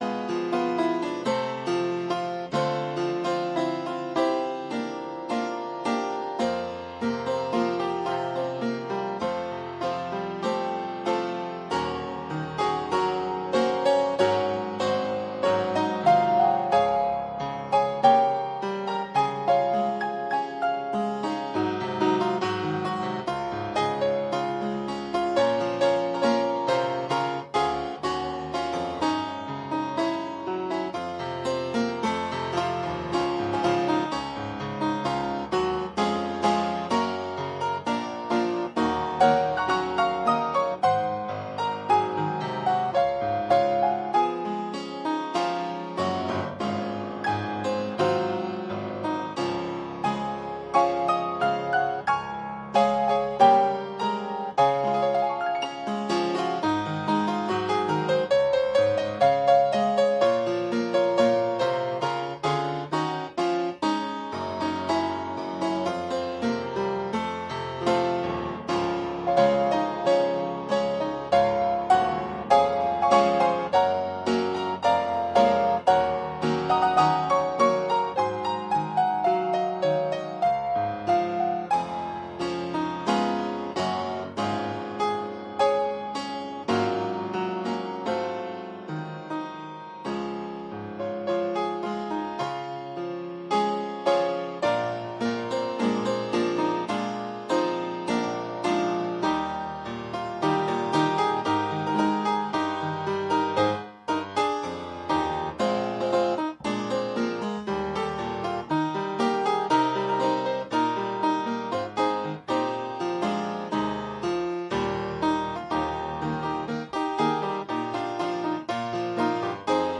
Mid Week Bible Study From Esther